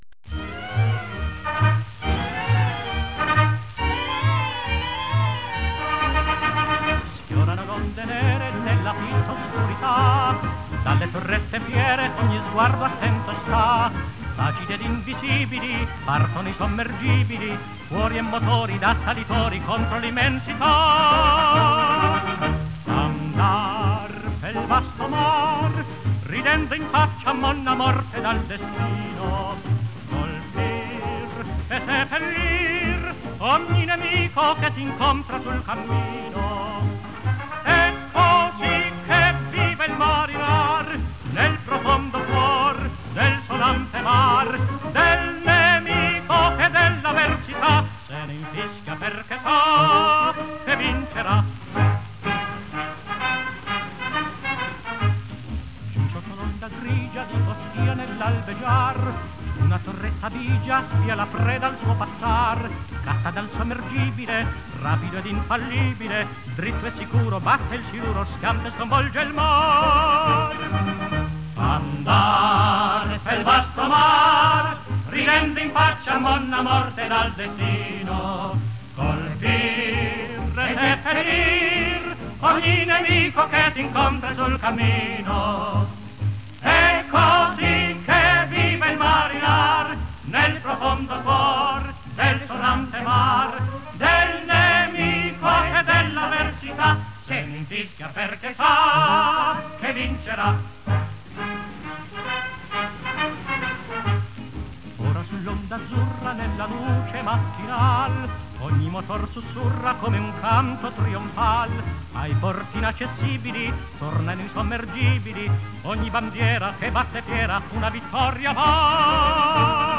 inno smg cantato.wav